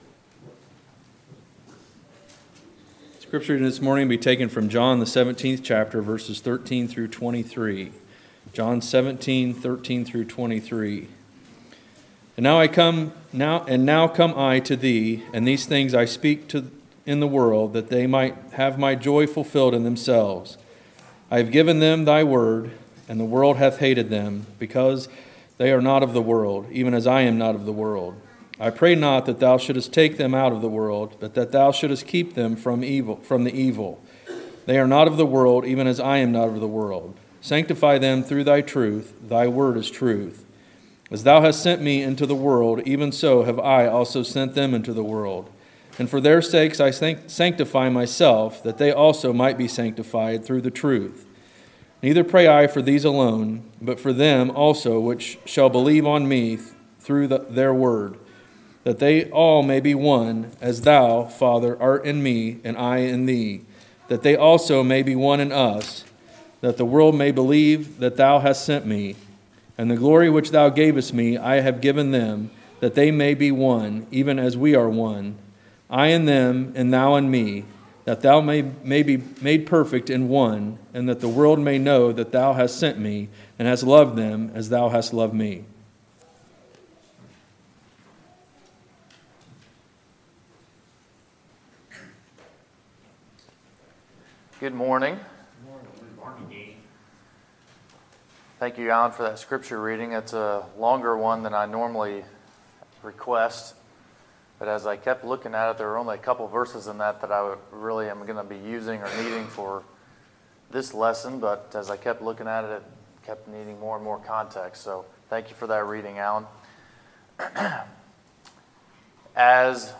Sermons, September 30, 2018